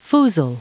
Prononciation du mot foozle en anglais (fichier audio)
Prononciation du mot : foozle